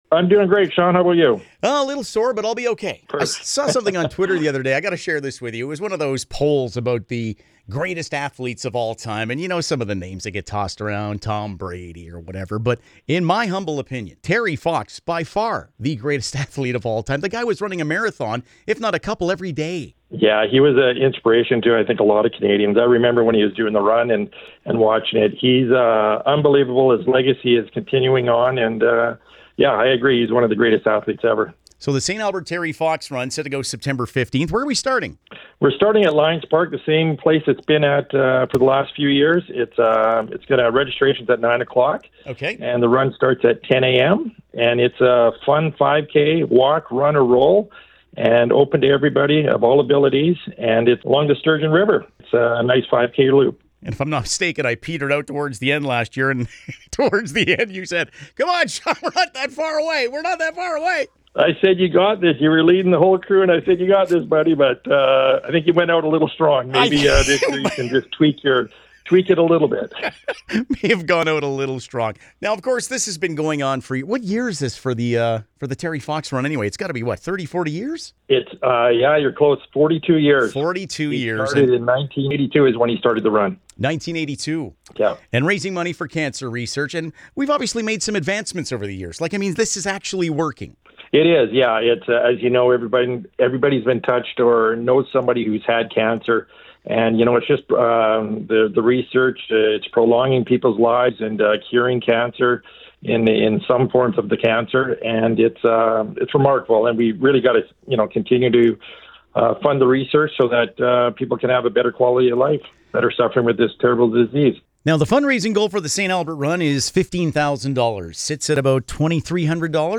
to get all the details.